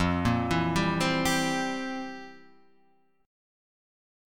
F Major 7th Suspended 2nd Suspended 4th